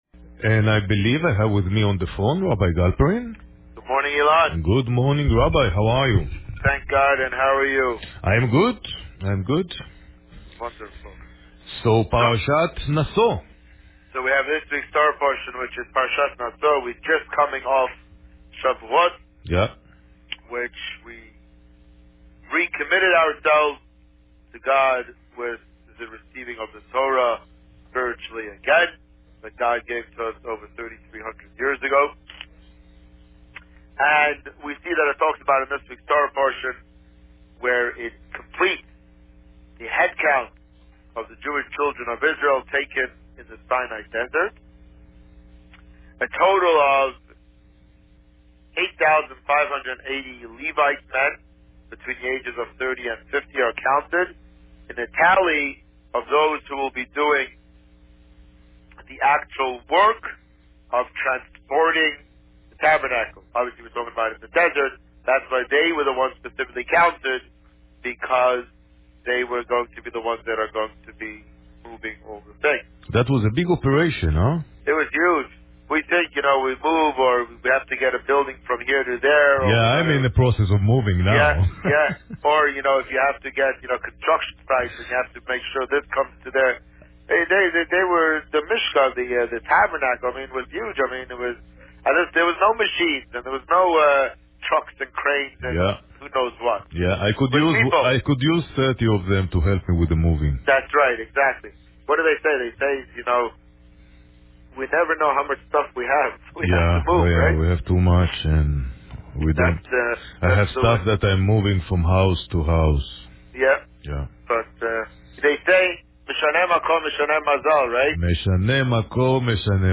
The Rabbi on Radio
This week, the Rabbi spoke about Parsha Naso. Listen to the interview here.